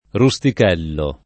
ruStik$llo] pers. m. stor.